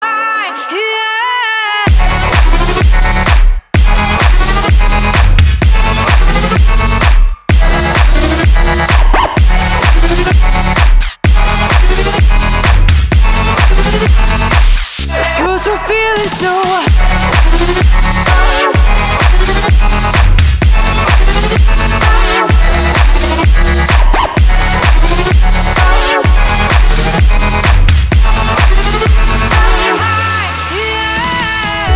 • Качество: 320, Stereo
громкие
жесткие
женский вокал
EDM
мощные басы
future house
энергичные